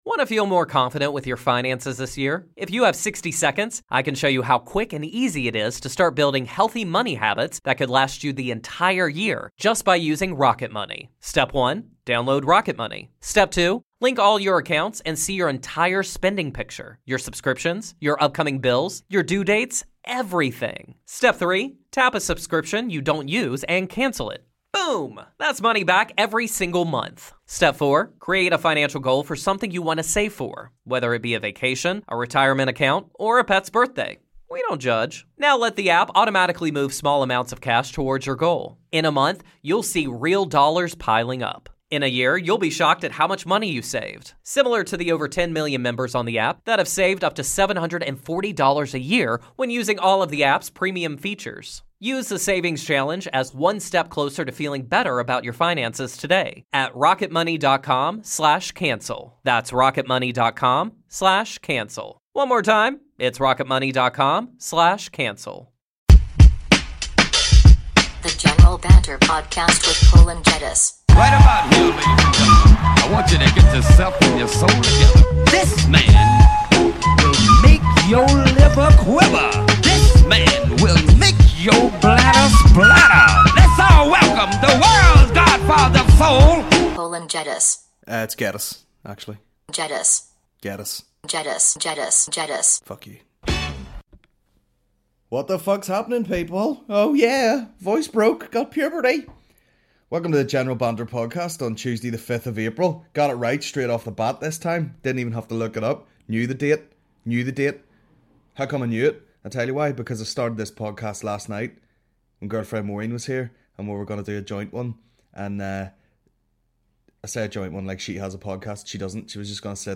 The General Banter Podcast is a Comedy podcast